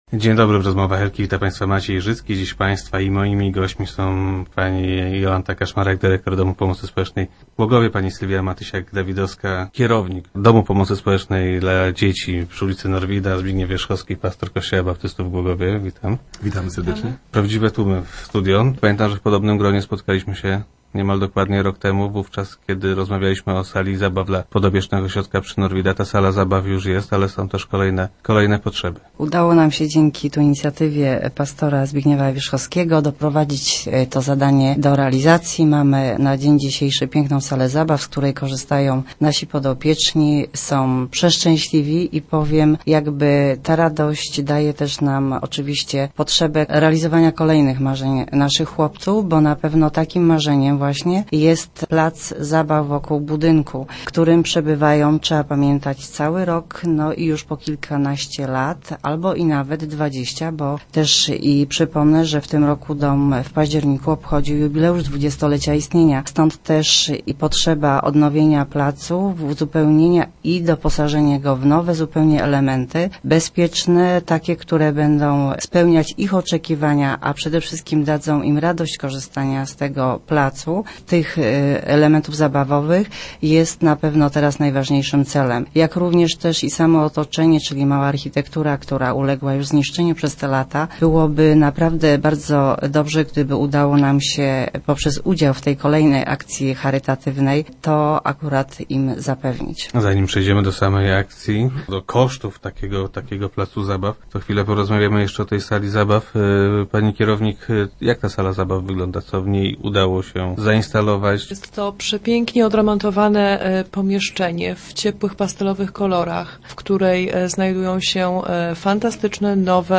mówiła na radiowej antenie